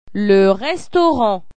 La nourriture   m'hoap